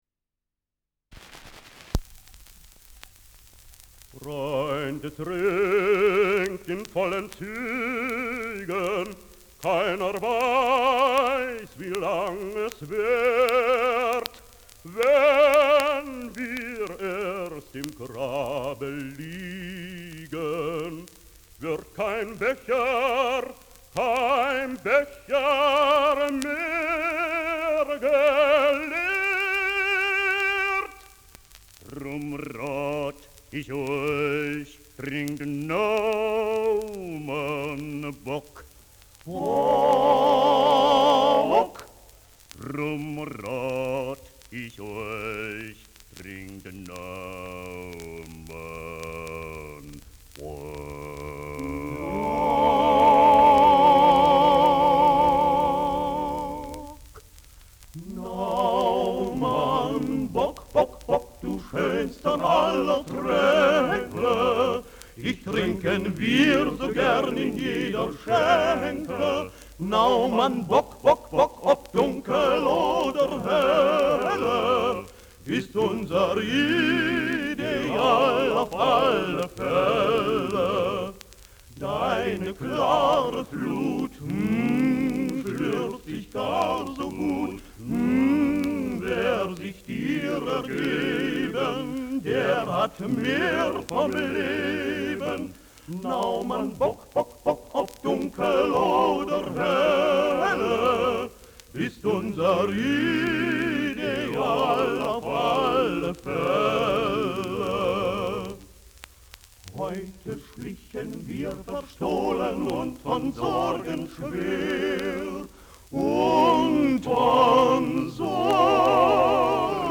Schellackplatte
Kaum abgespielt : häufiges leichtes Knacken